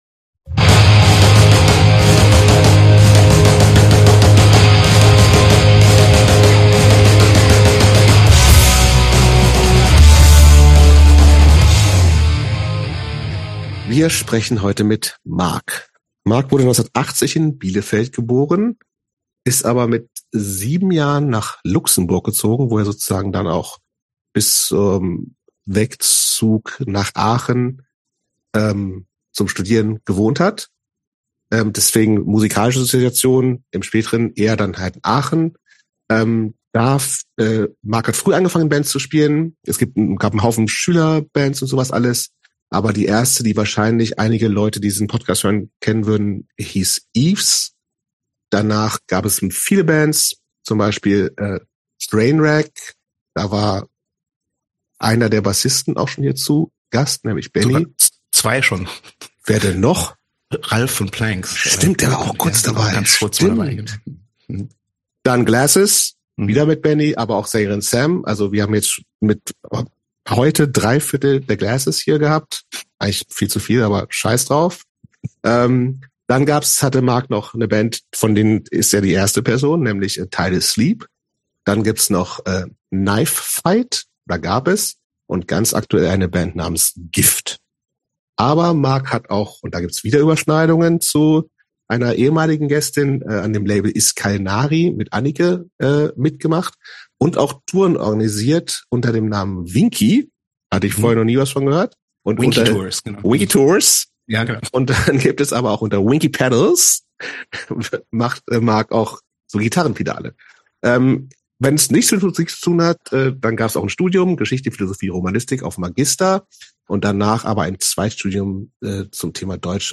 Gespräche über früher und heute.